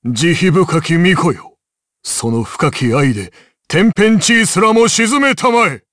Dakaris-Vox_Skill3_jp.wav